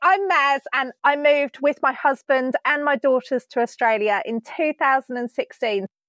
Seeking tips to improve distant sounding voice
To me, it sounds like the lady is quite distant from the microphone…
The good news is that it’s not damaged, distorted, or broken. It’s just low volume. It is a little odd that it strongly resists loudness boosting. It’s not a stereo or cancellation problem. It does that in mono, too.
It’s more like noise-reduction* is turned up too high.